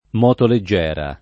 [ m q tole JJ$ ra ]